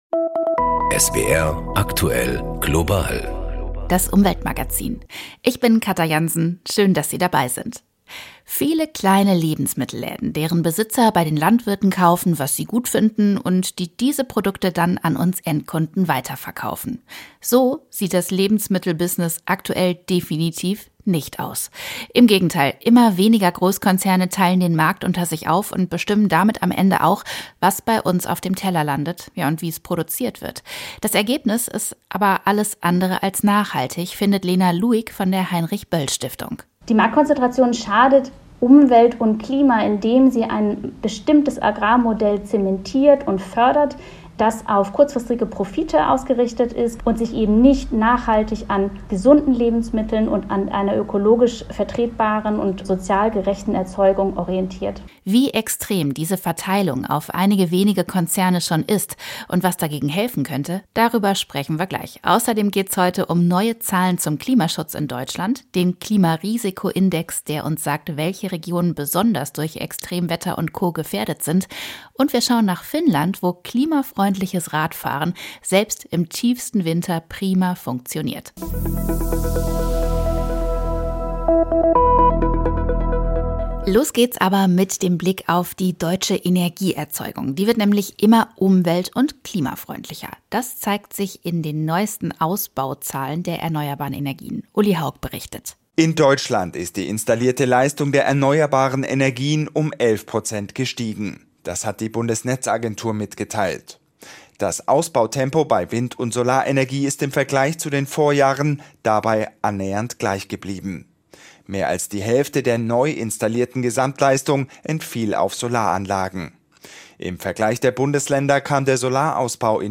Außerdem geht’s heute um neue Zahlen zum Klimaschutz in Deutschland und wir schauen nach Finnland, wo klimafreundliches Radfahren selbst im tiefsten Winter prima funktioniert. Eine Sendung